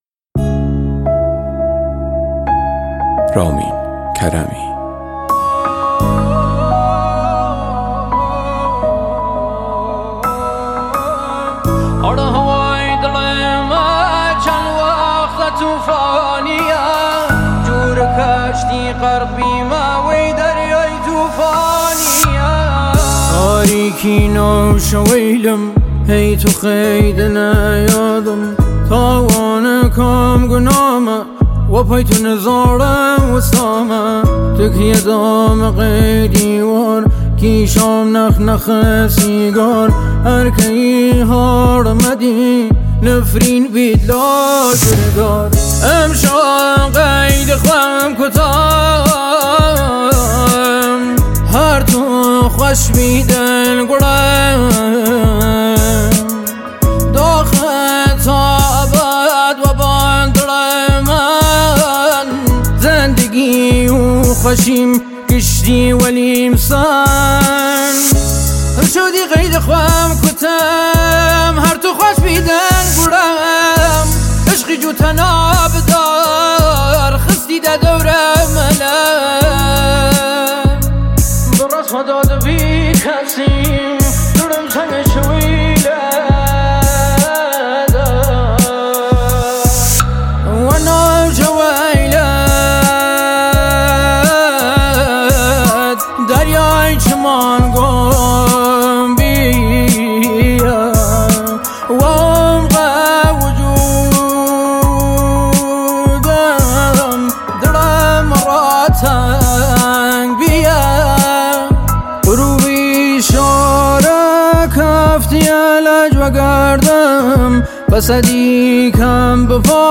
پاپ کردی عاشقانه غمگین